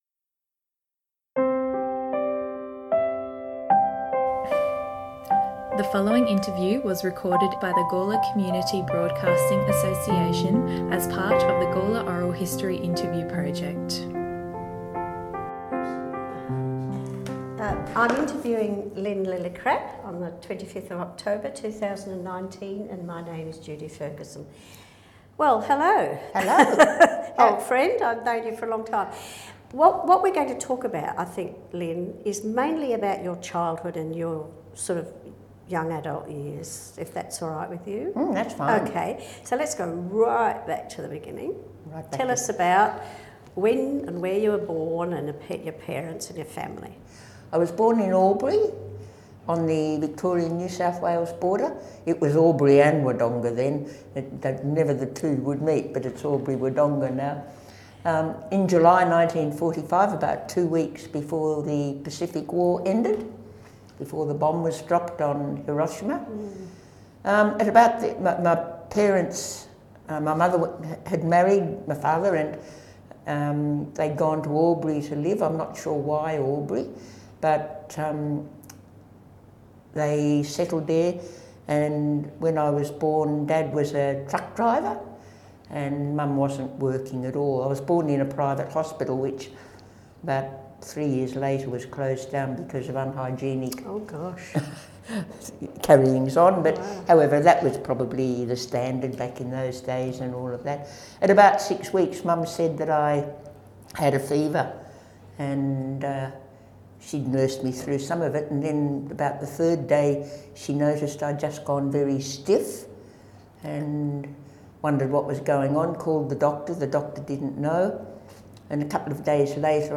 Oral History
The above recorded interview contains music sourced from the Free Music Archive.